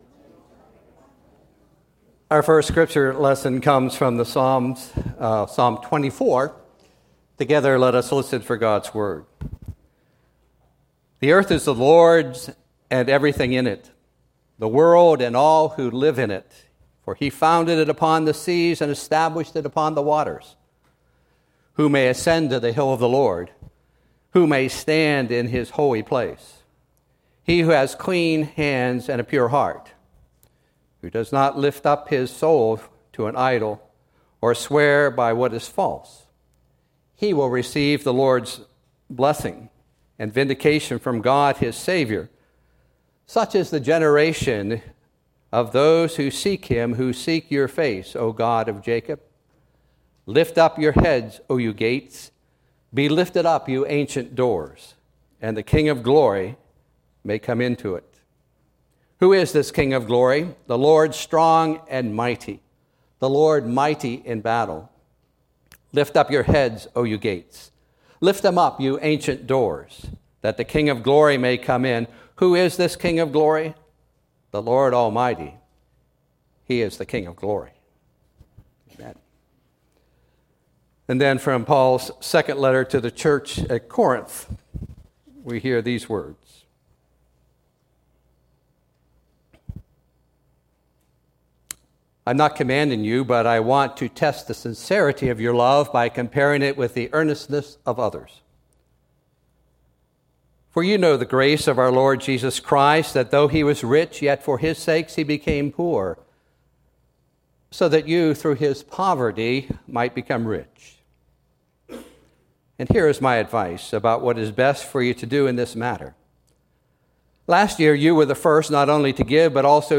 Sermons – Page 39 – First Presbyterian Church
Sunday Worship